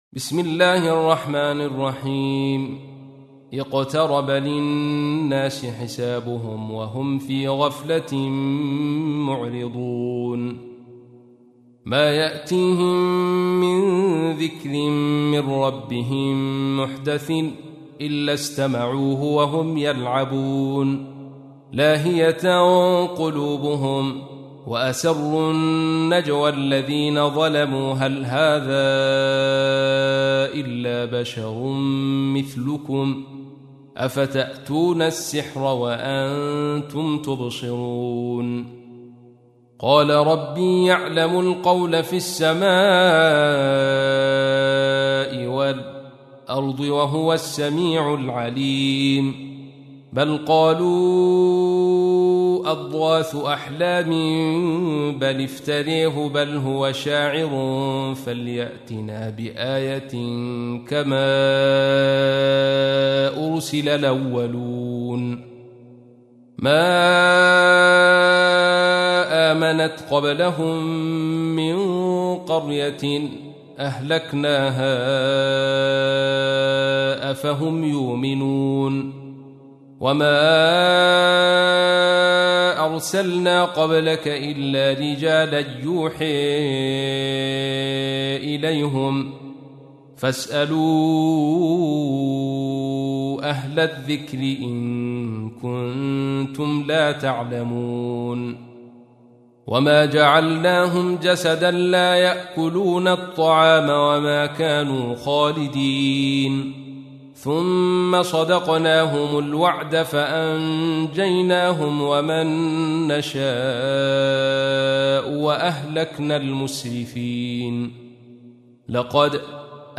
تحميل : 21. سورة الأنبياء / القارئ عبد الرشيد صوفي / القرآن الكريم / موقع يا حسين